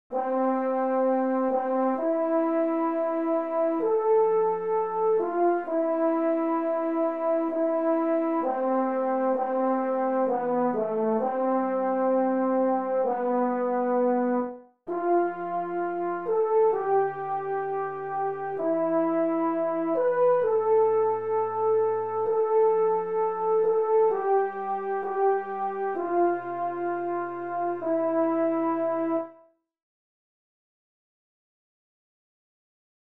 Chorproben MIDI-Files 514 midi files